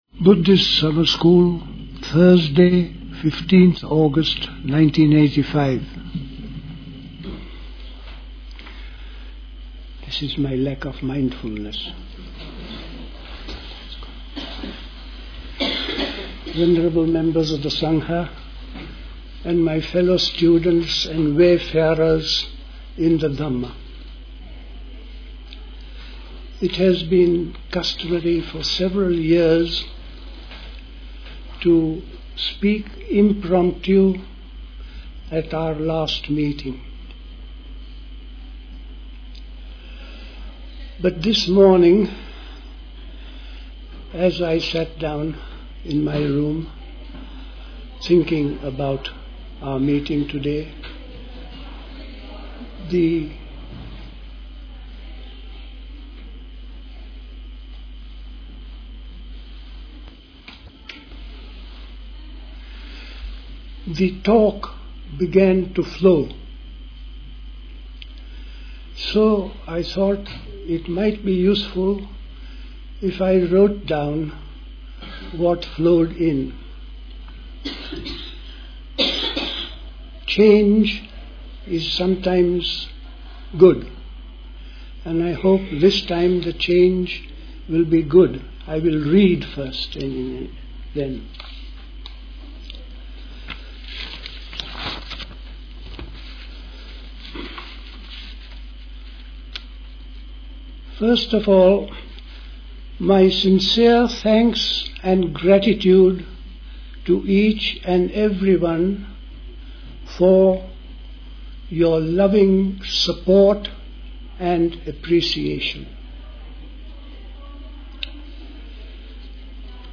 Play Talk